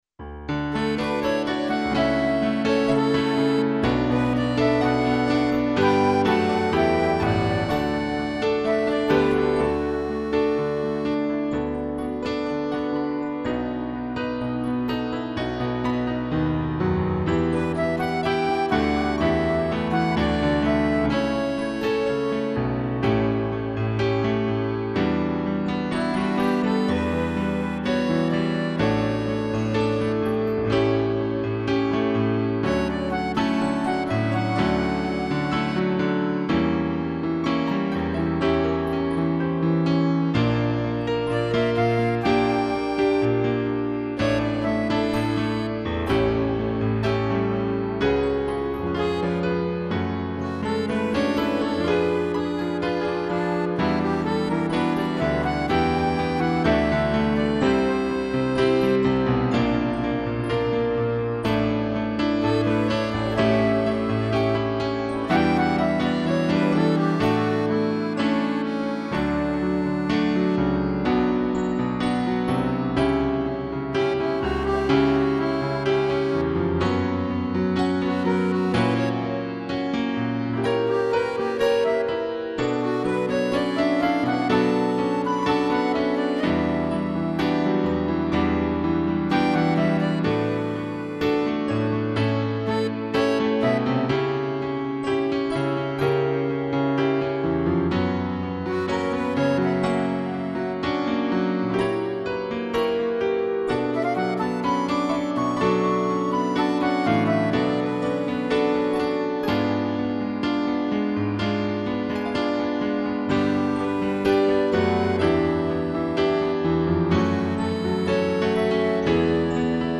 2 pianos, acordeão e flauta